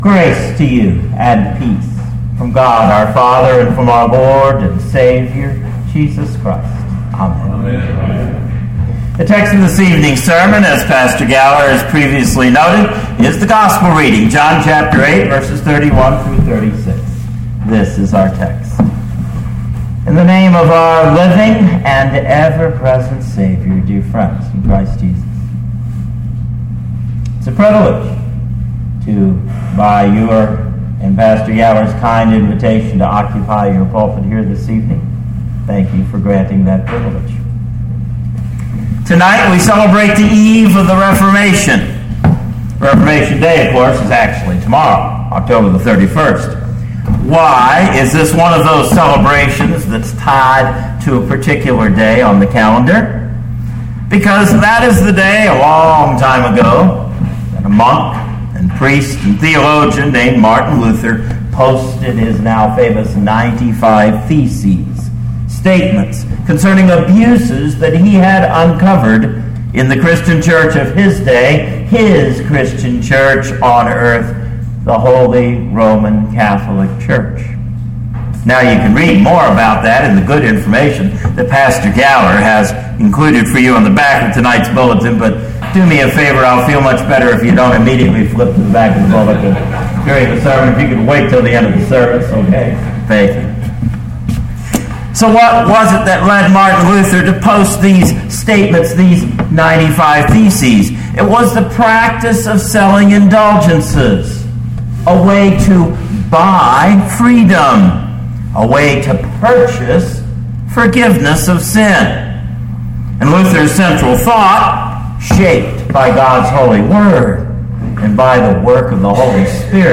2013 John 8:31-36 Listen to the sermon with the player below, or, download the audio.